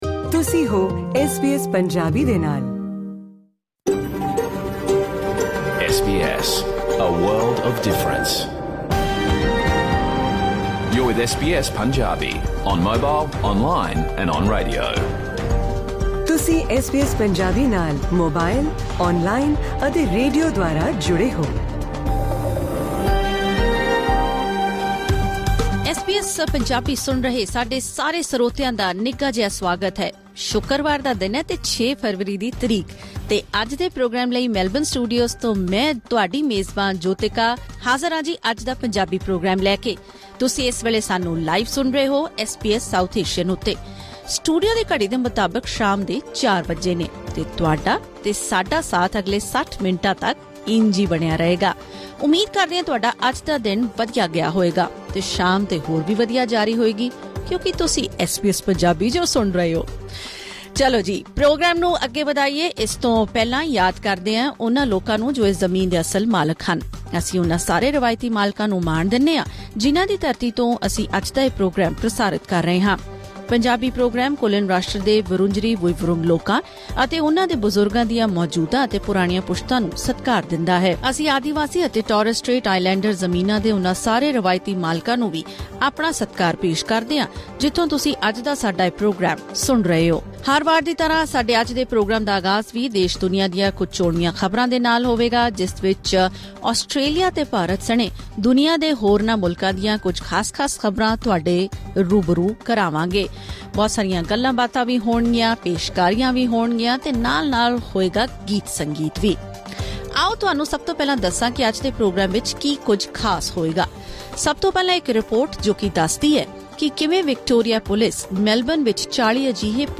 ਐਸ ਬੀ ਐਸ ਪੰਜਾਬੀ ਦਾ ਰੇਡੀਓ ਪ੍ਰੋਗਰਾਮ ਸੋਮਵਾਰ ਤੋਂ ਸ਼ੁੱਕਰਵਾਰ ਸ਼ਾਮ 4 ਵਜੇ ਤੋਂ 5 ਵਜੇ ਤੱਕ ਲਾਈਵ ਪ੍ਰਸਾਰਿਤ ਹੁੰਦਾ ਹੈ।